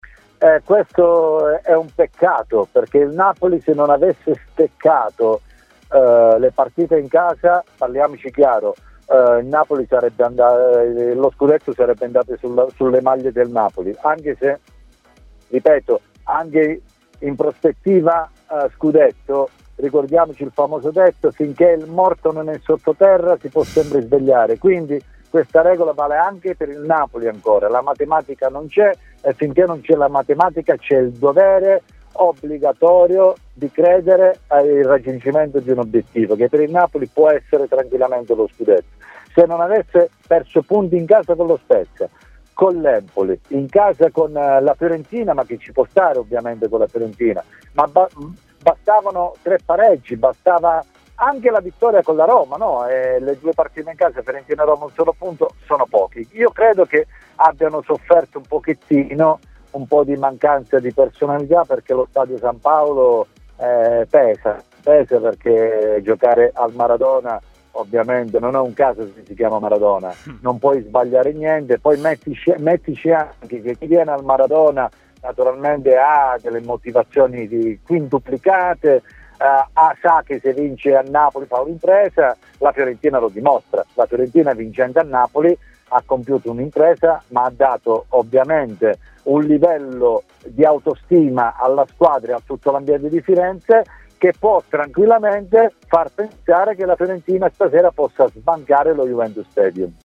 L'ex difensore del Napoli Vittorio Tosto ha parlato ai microfoni di Tmw Radio.